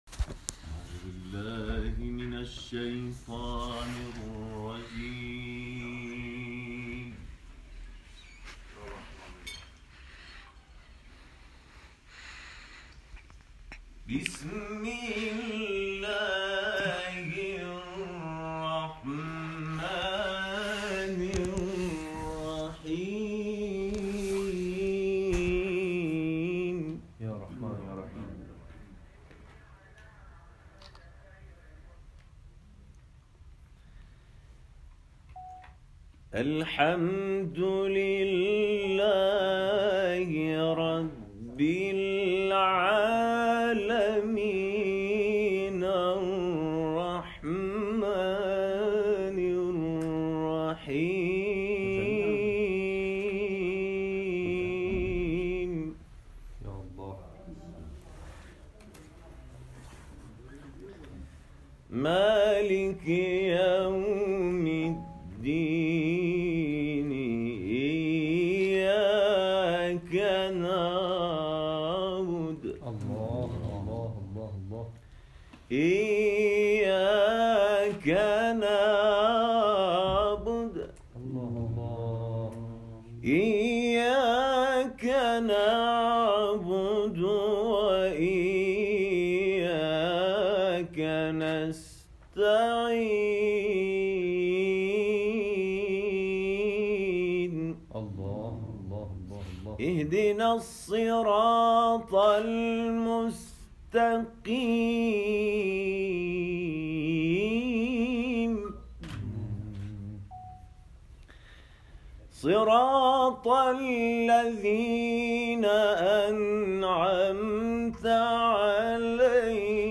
قاریان این کاروان در دفتر امام جمعه باغین، گلزار شهدای باغین و ... به تلاوت نور پرداختند.
تلاوت